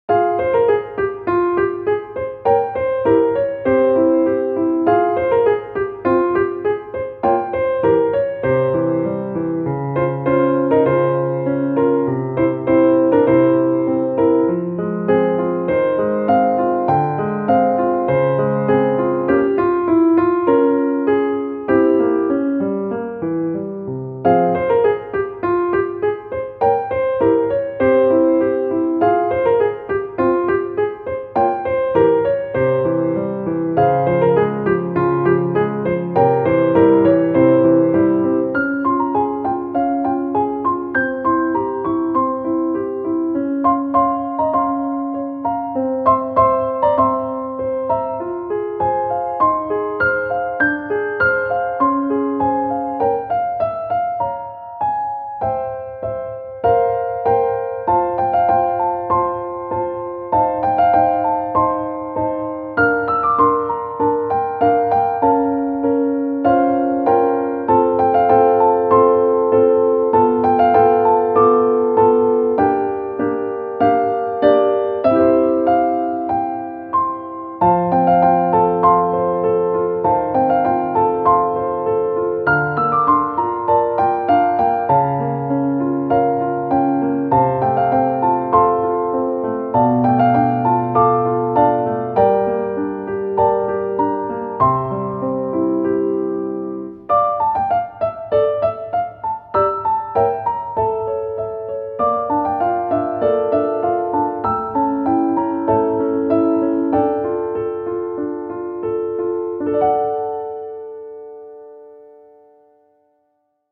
• 暗めのしっとりしたピアノ曲のフリー音源を公開しています。
ogg(L) - 不思議 かわいい おしゃれ